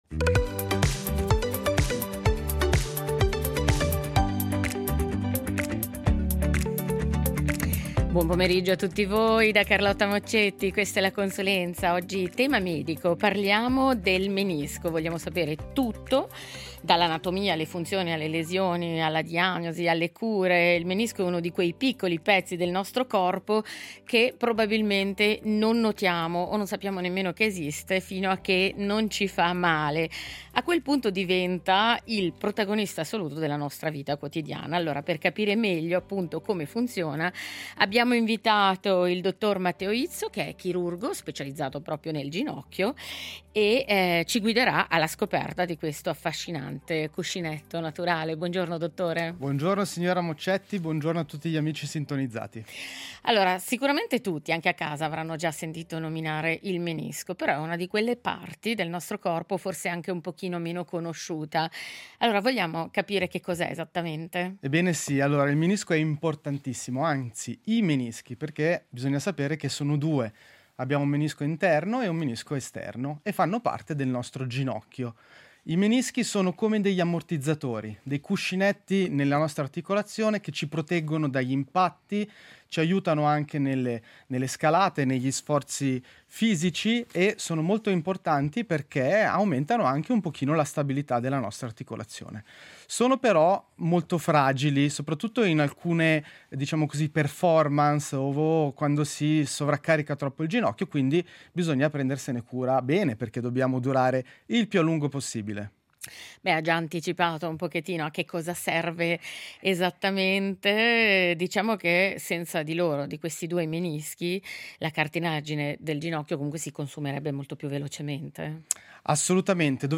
chirurgo ortopedico specializzato in ginocchio